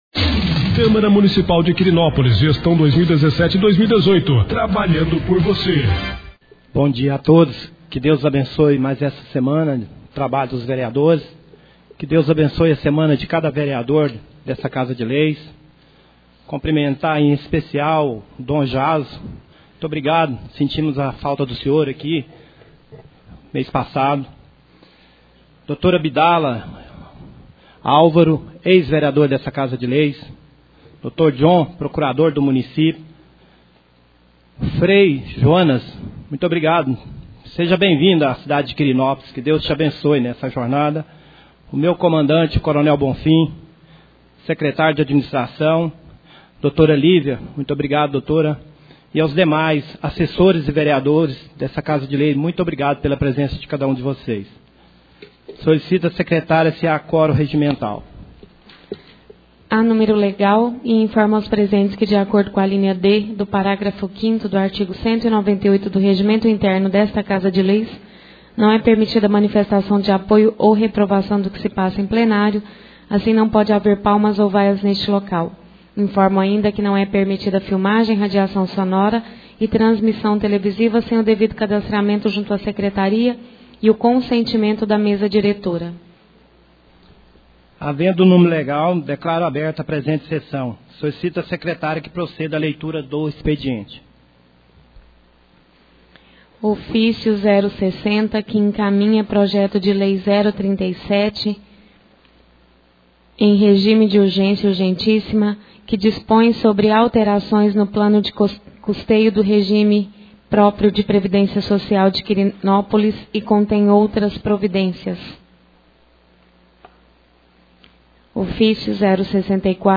1ª Sessão Ordinária do Mês de Novembro 2017